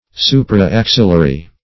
Search Result for " supra-axillary" : The Collaborative International Dictionary of English v.0.48: Supra-axillary \Su"pra-ax"il*la*ry\, a. (Bot.)